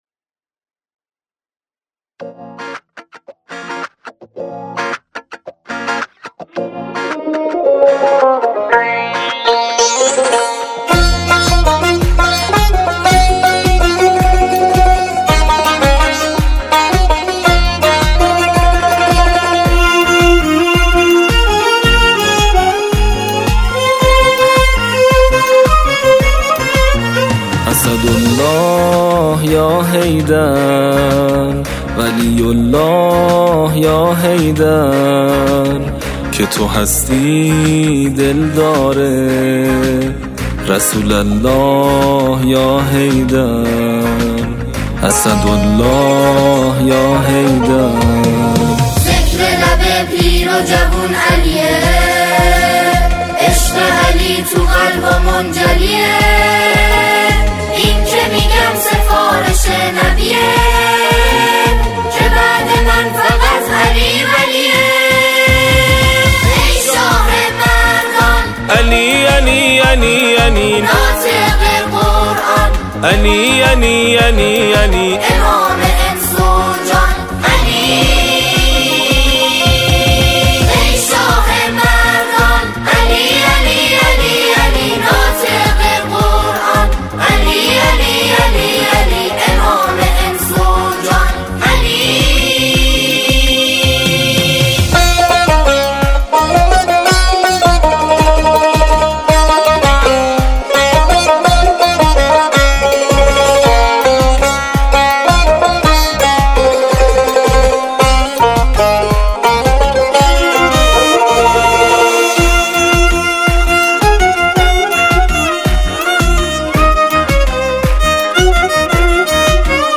به صورت جمع خوانی